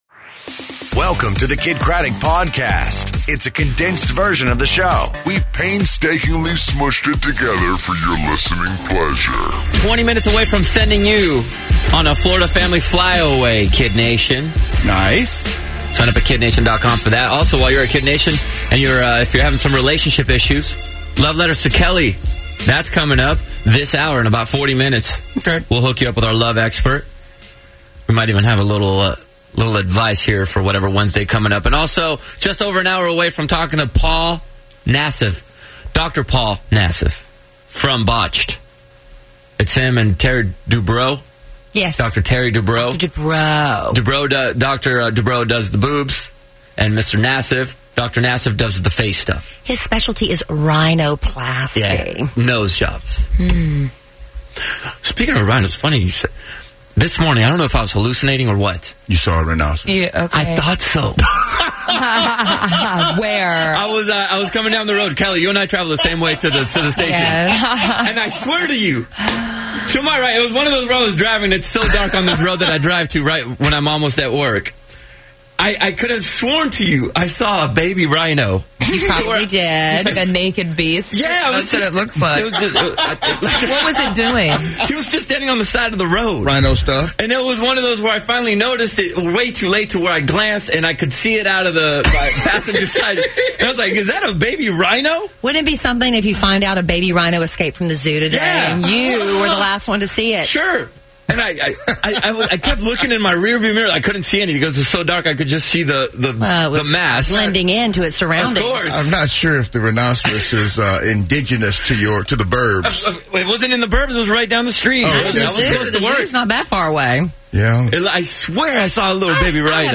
And Dr. Nassif From Botched On The Phone